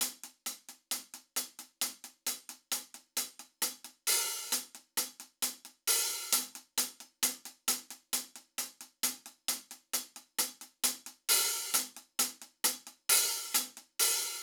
TC3LiveHihatLoop.wav